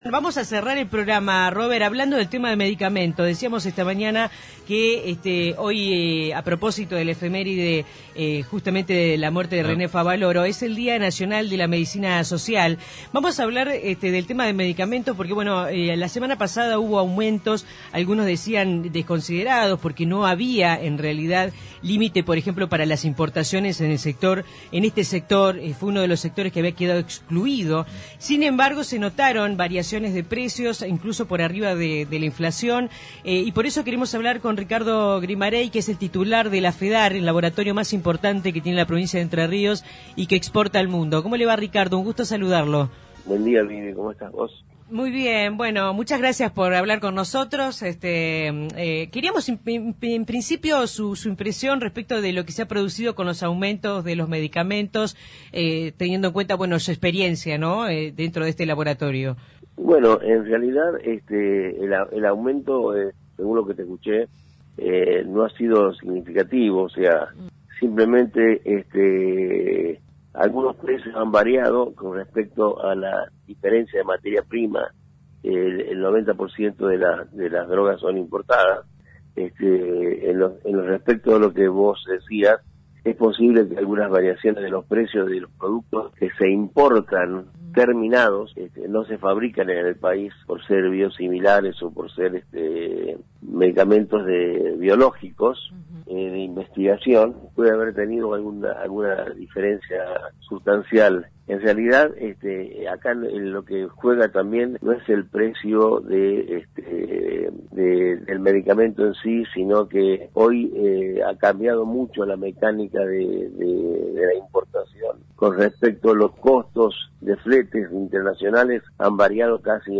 En la charla radial se le hizo notar al entrevistado que en lo que respecta a insumos activos para medicamentos quedaron excluidas de las limitaciones de importaciones, por lo que se supone que ahí no debería haber existido un impacto en los precios.